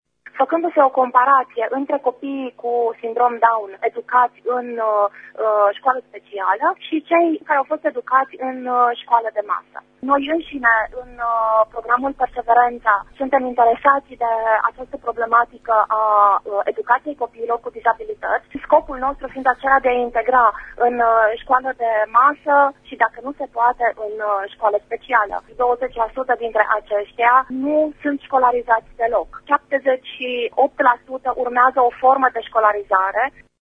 Ea spune că, în România, 20% dintre aceștia nu sunt școlarizați deloc: